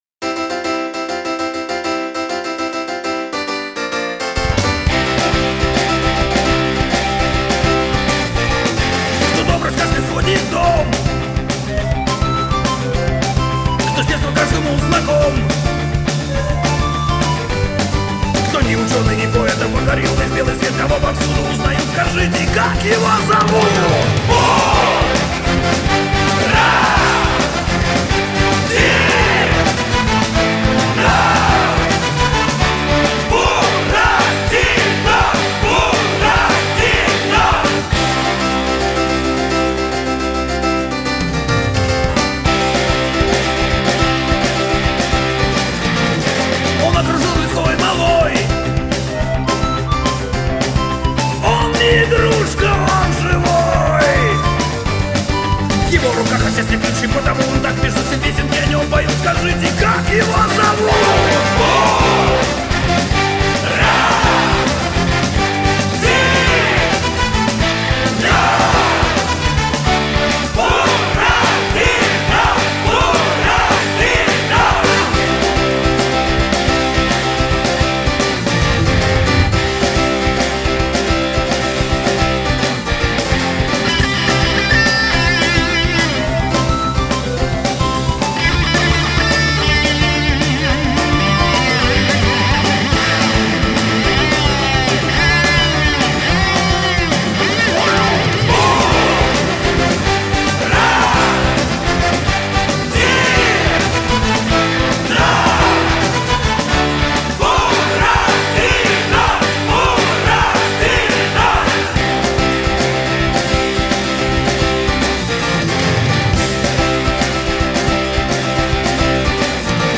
(+) рок обработка